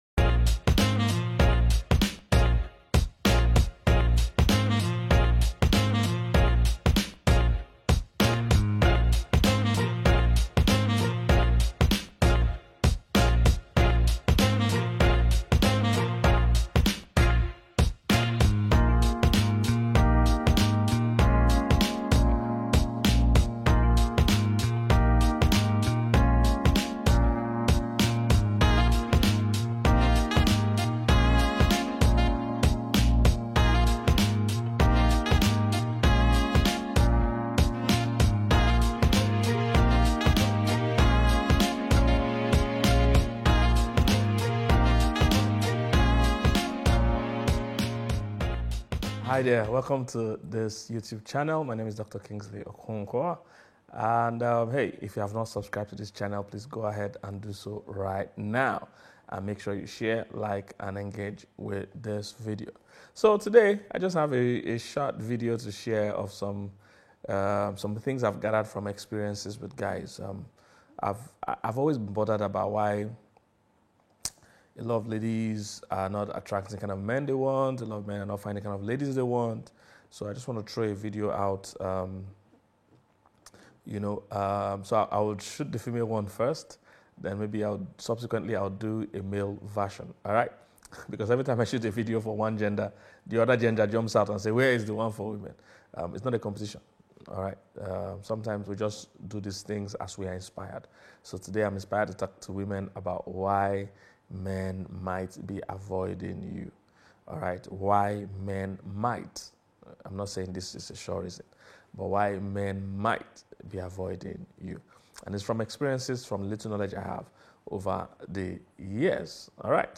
Here’s a message/sermon that is set to change your life.